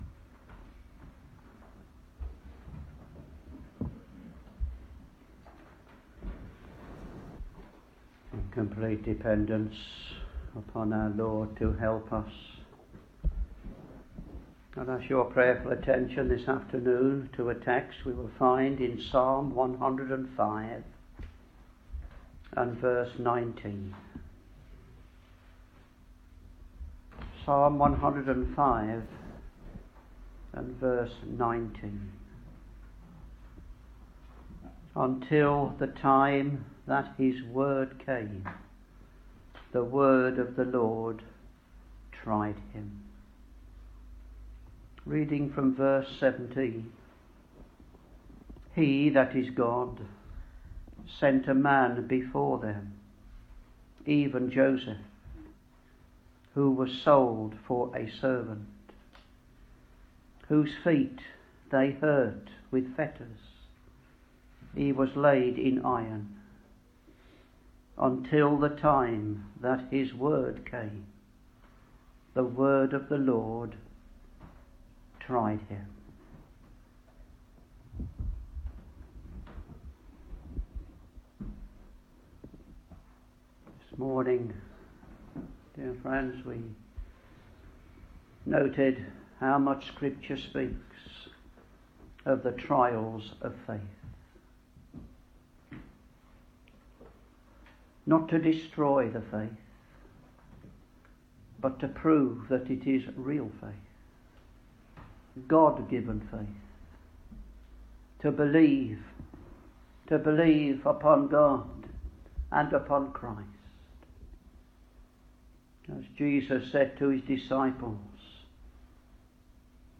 Sermons Psalm 105 v.19 Until the time that his word came: the word of the LORD tried him